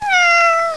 Gat salvatge